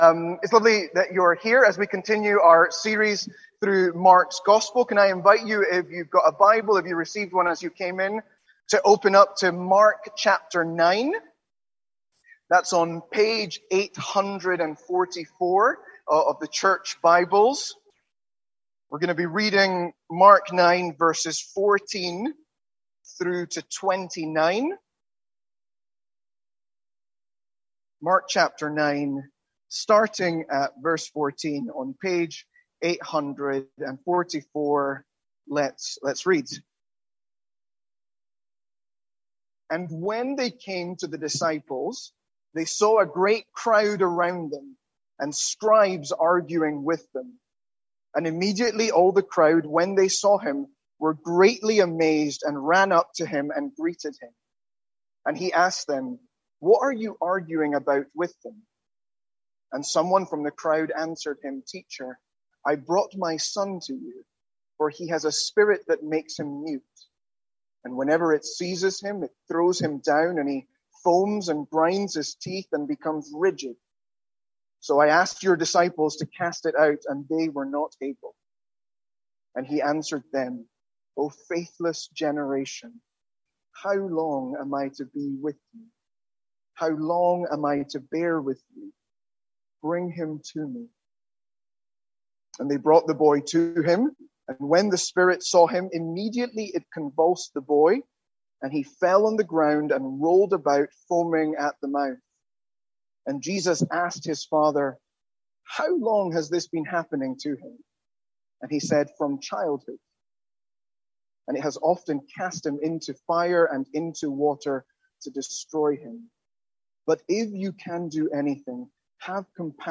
From our evening series in Mark.